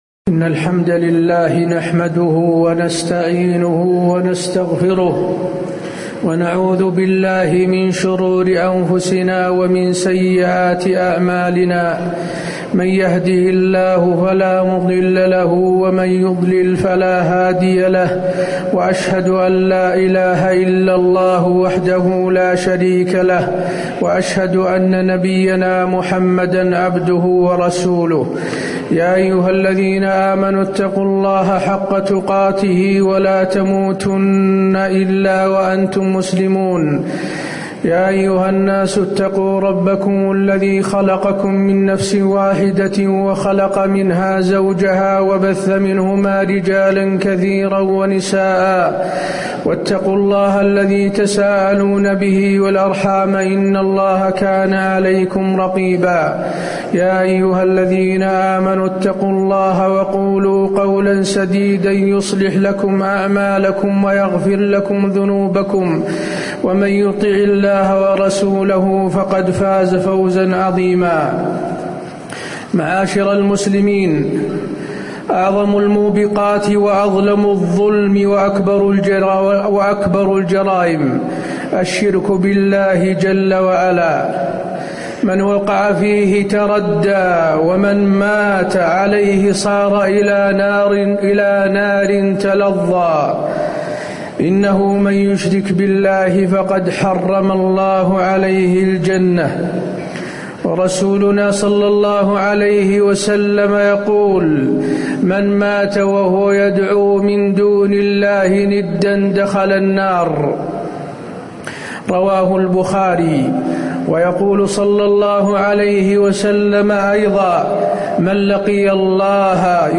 تاريخ النشر ٢٢ ذو الحجة ١٤٣٧ هـ المكان: المسجد النبوي الشيخ: فضيلة الشيخ د. حسين بن عبدالعزيز آل الشيخ فضيلة الشيخ د. حسين بن عبدالعزيز آل الشيخ من صور الشرك الأكبر